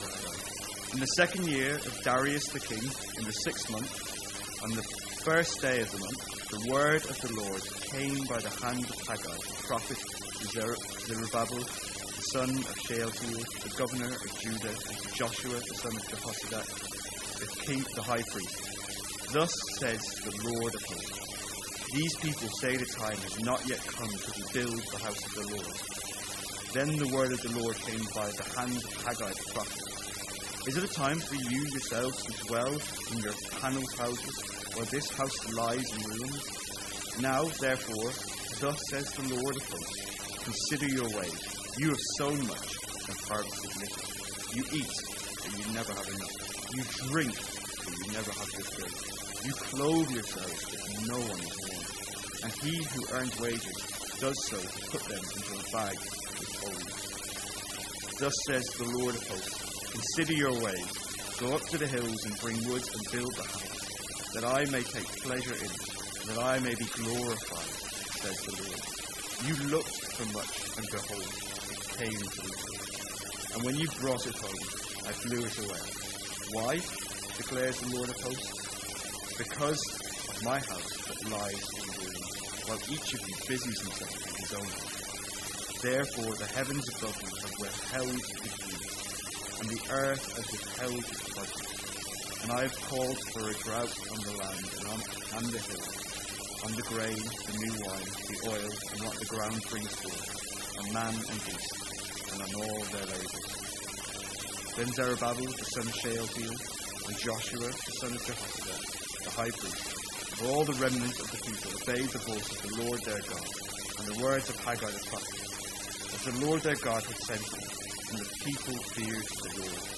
The second talk on Haggai from MYC 2017.